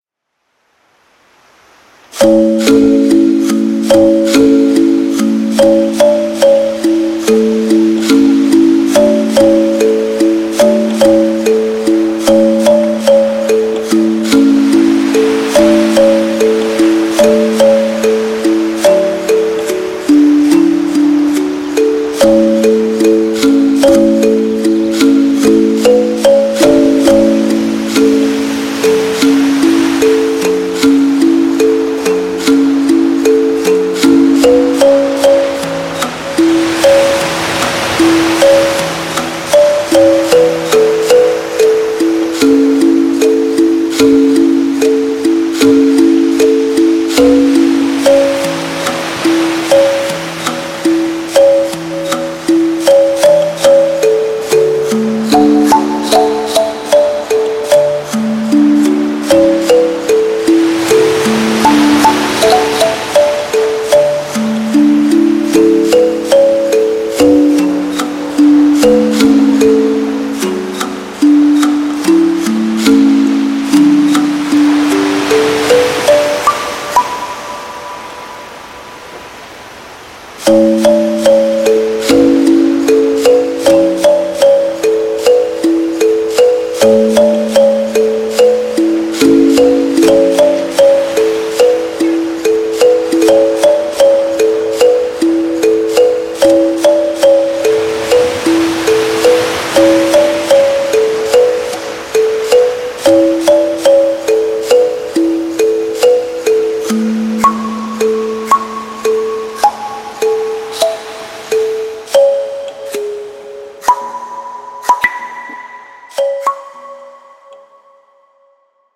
• Жанр: Детские песни
🎶 Детские песни / Музыка детям 🎵 / Музыка для новорожденных